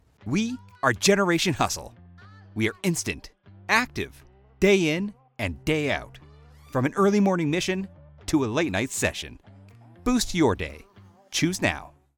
Male
I provide a warm, deep range with various styles.
Television Spots
Energetic Read
Words that describe my voice are Deep, Warm, Narrative.
All our voice actors have professional broadcast quality recording studios.